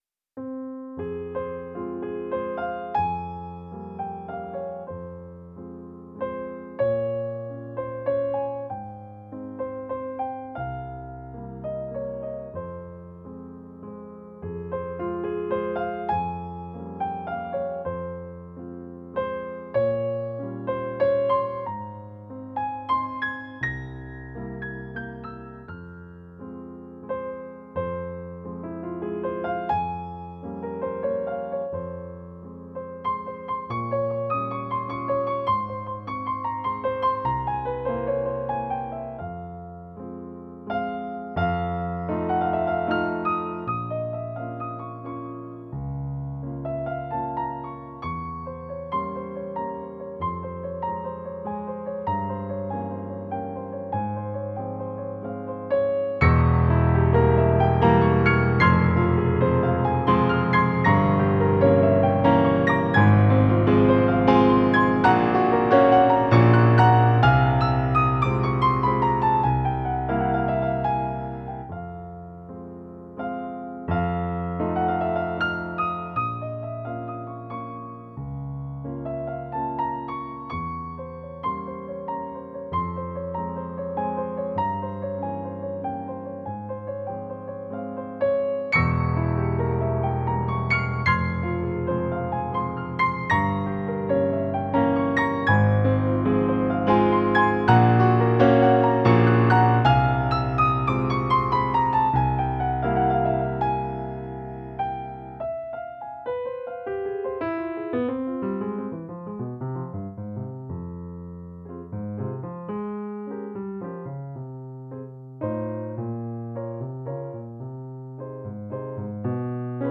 SOLO PIANO
• Genre: Romantic, Waltz